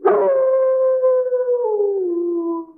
bdog_howl_0.ogg